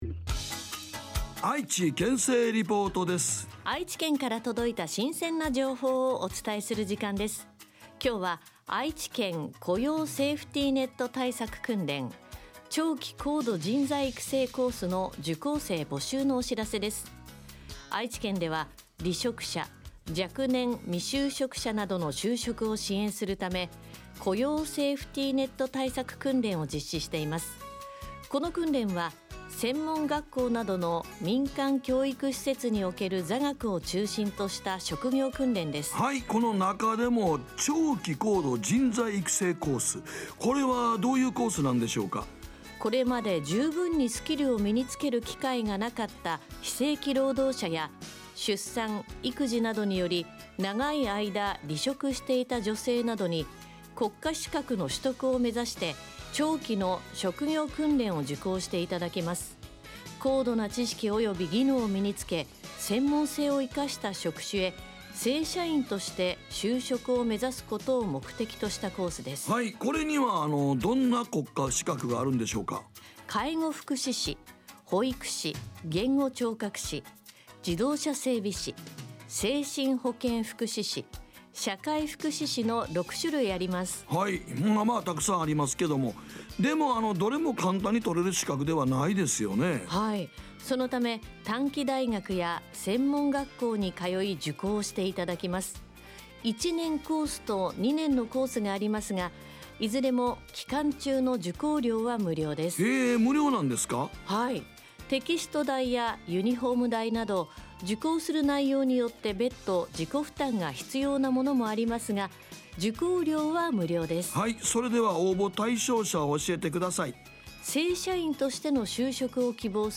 広報ラジオ番組